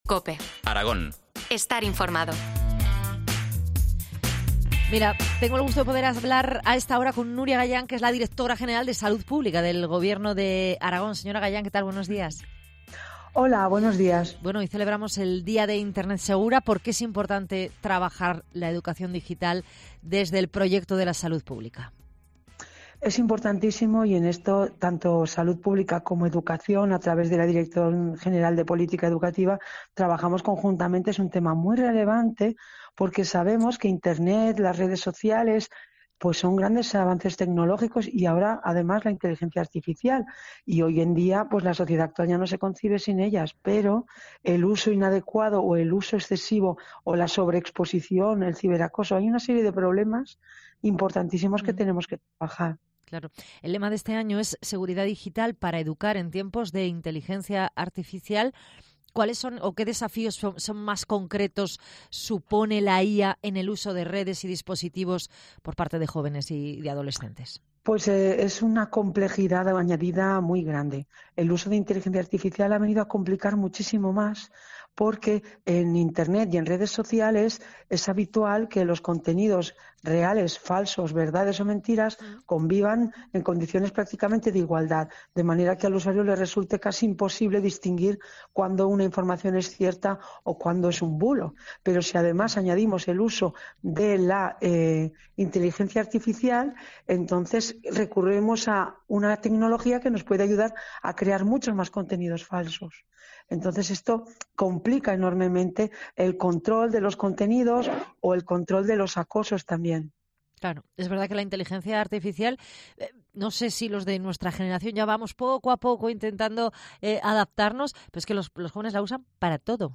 Entrevista a Nuria Gayán, directora general de salud del Gobierno de Aragón, sobre Pantallas Sanas